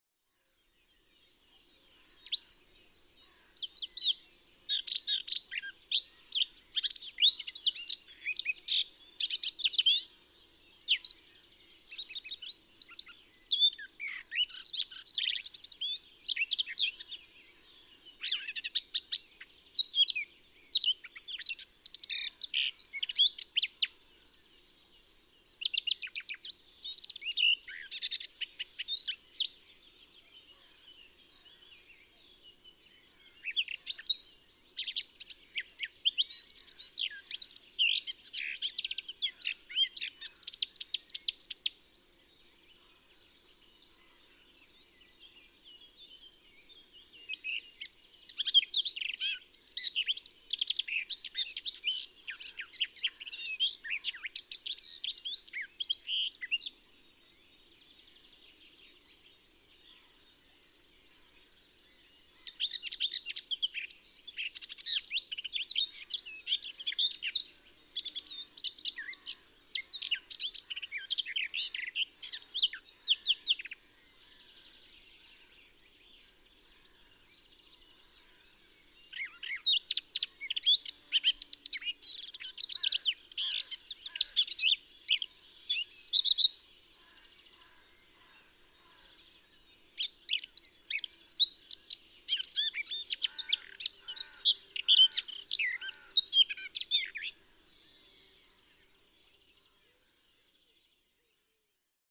Whilst sorting out recordings for the 2018 Wildlife Sound Recording Society annual
Still learning (single individual)
Eurasian Blackcap Sylvia a. atricapilla, 2cy male, late plastic song